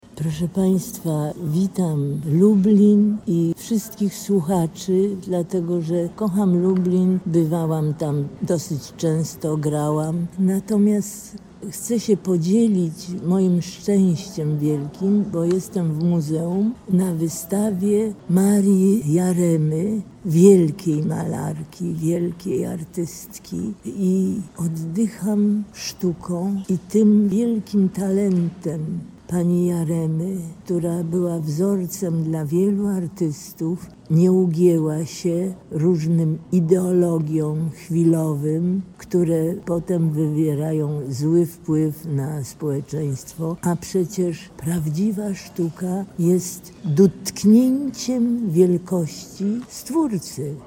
aktorki Emilii Krakowskiej, która zwróciła uwagę na drogę artystyczną Marii Jaremy: